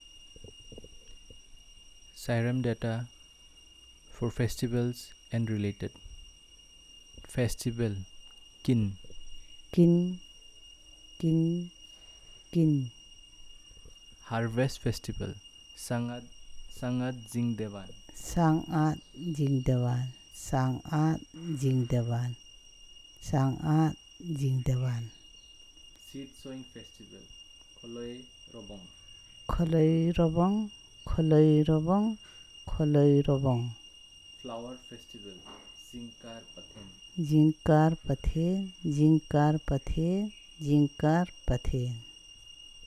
Elicitation of words about festivals and related